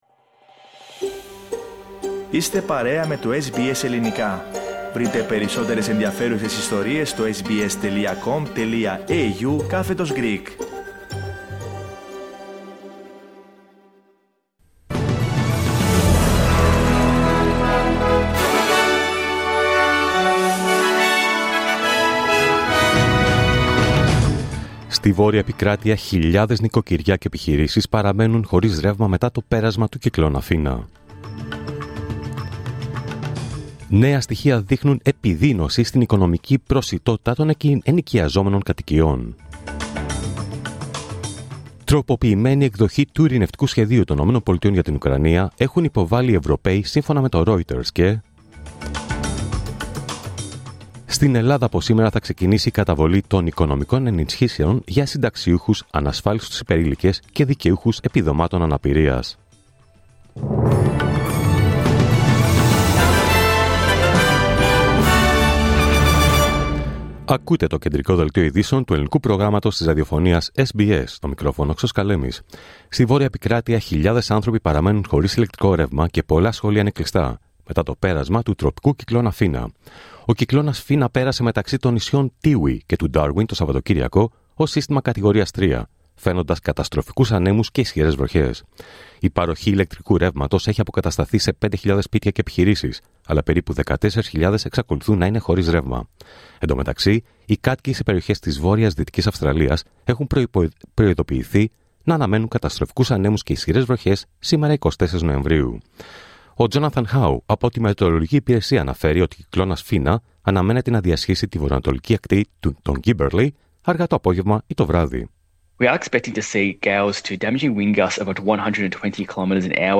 Δελτίο Ειδήσεων Δευτέρα 24 Νοεμβρίου 2025